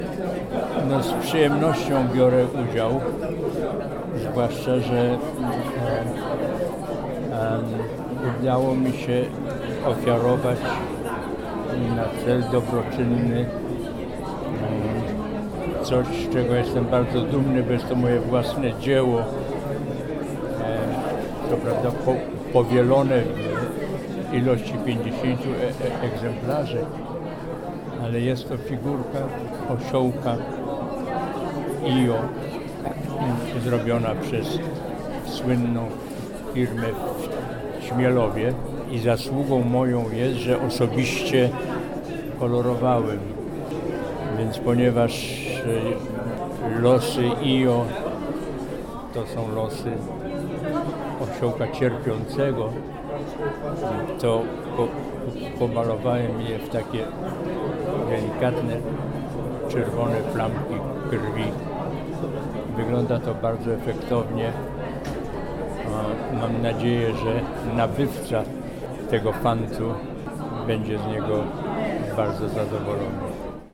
Jerzy-Skolimowski-rezyser.mp3